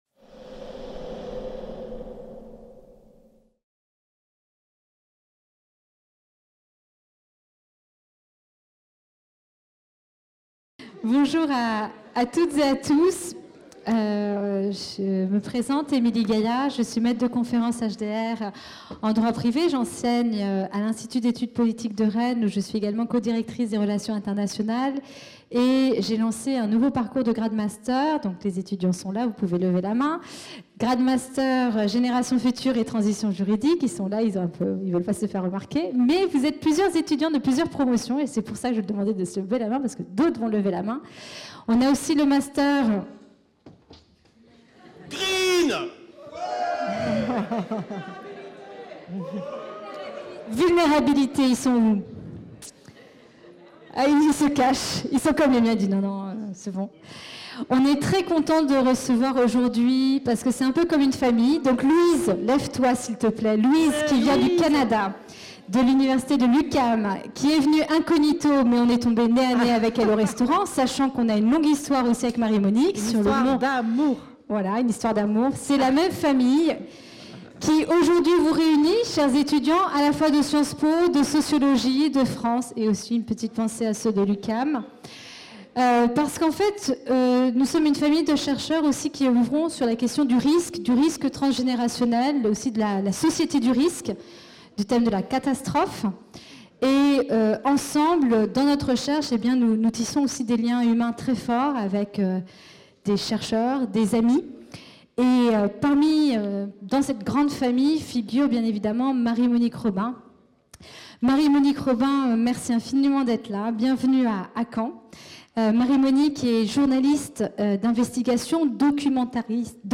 Organisée par la Chaire Normandie pour la Paix en partenariat avec Sciences Po Rennes, Arènes UMR6051, l'Université de Caen Normandie et le centre de recherche risques et vulnérabilités, la conférence mettant en avant le travail de Marie Monique Robin sur les causes des pandémies.